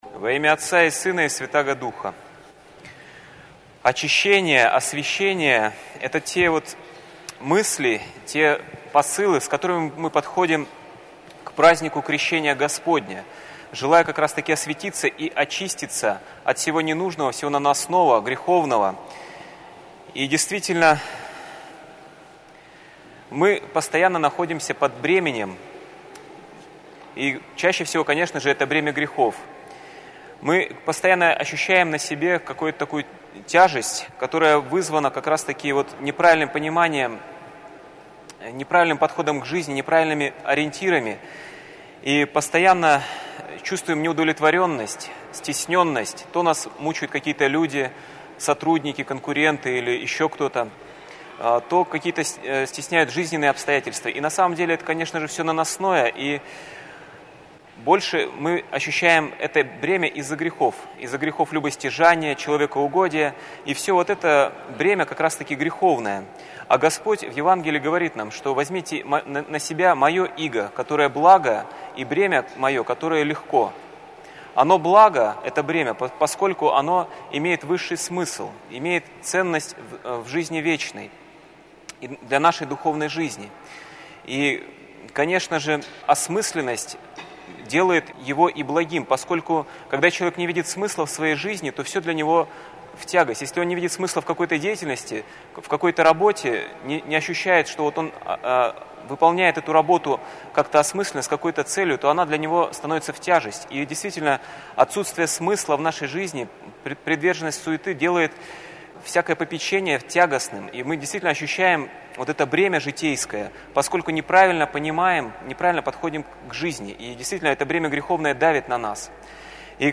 ПРОПОВЕДЬ
Проповедь на Всенощном бдении